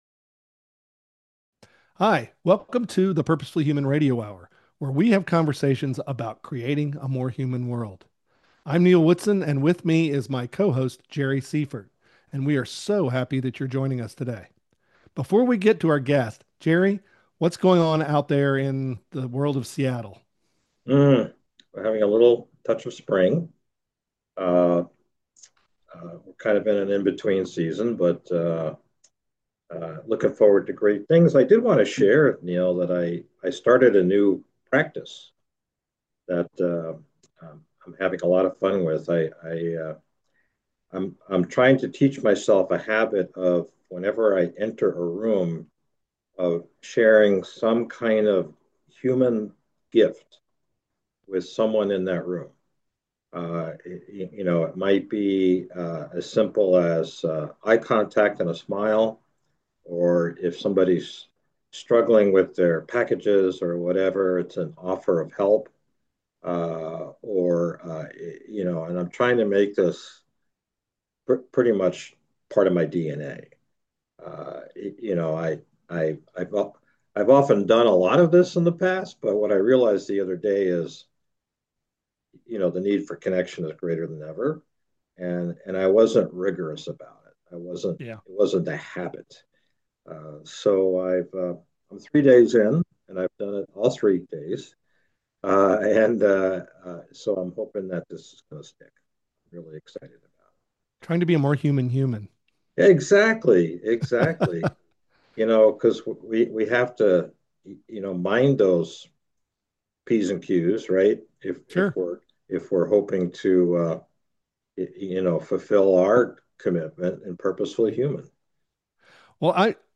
In the PurposeFully Human Radio Hour we talk to interesting people about the need for more humanity in our world and get their ideas for moving in that direction.